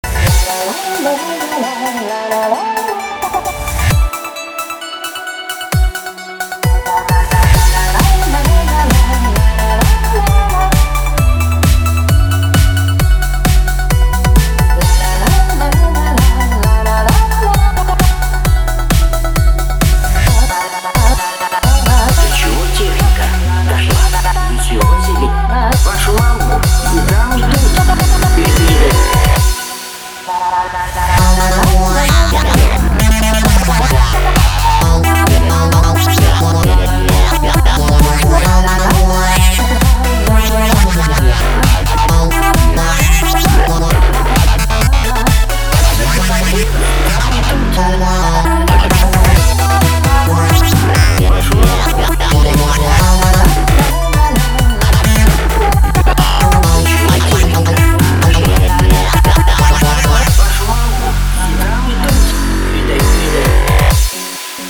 • Качество: 320, Stereo
громкие
dance
Electronic
club
в необычной аранжировке.